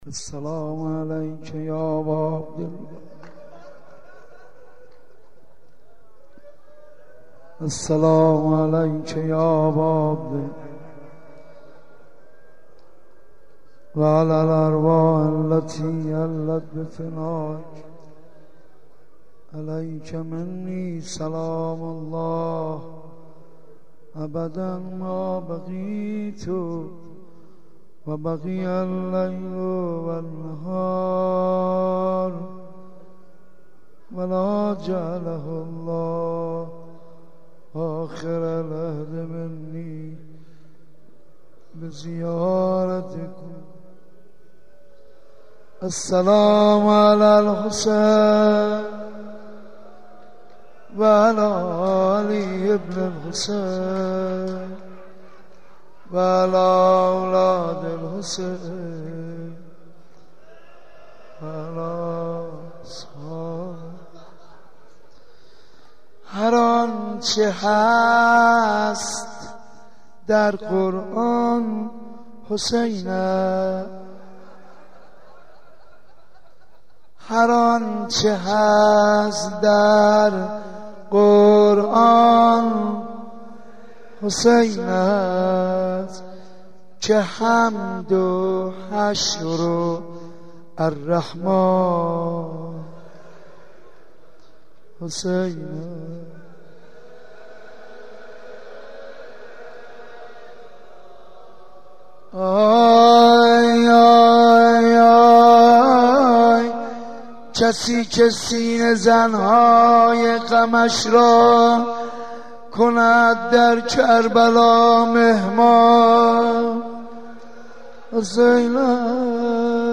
roze 10 moharram 81 ark.mp3